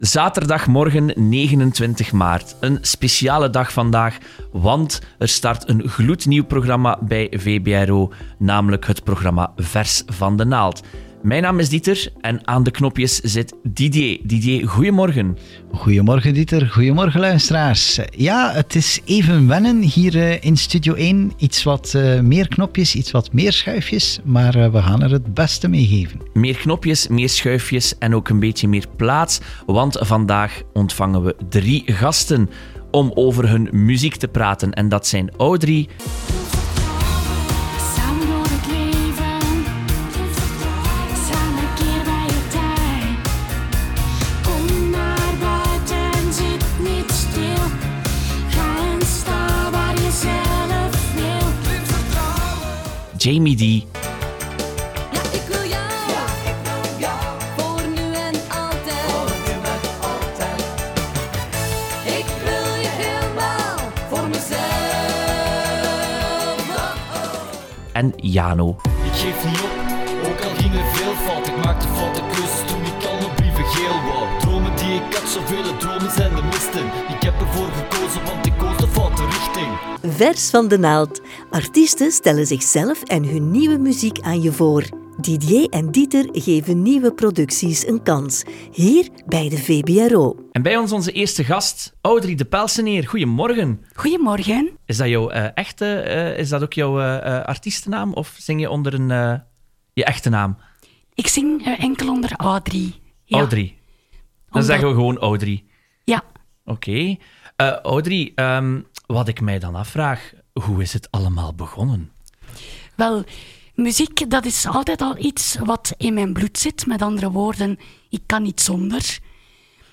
Achter de schermen bij ‘Vers van de Naald’, het volledig interview nu via onze podcast!